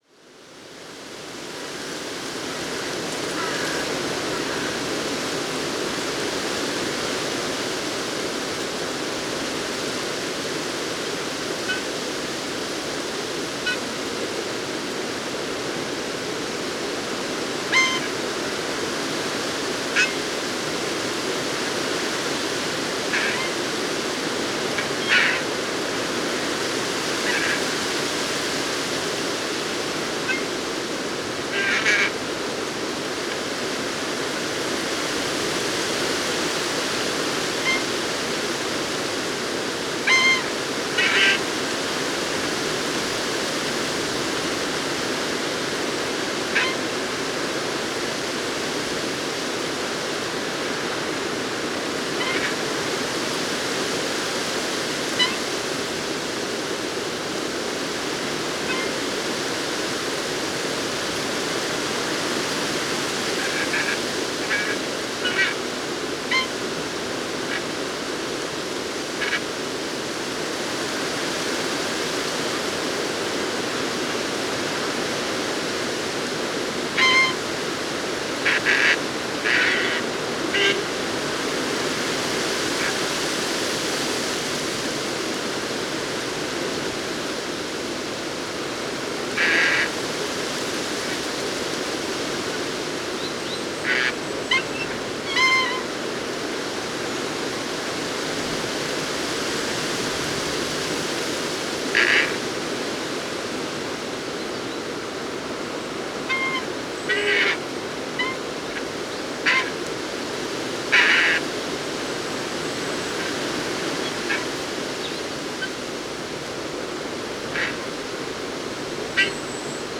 seemingly of geese, always from the same direction.
So the mystery sound turned out
as the creaking sounds of a tree.
081022, squeaking tree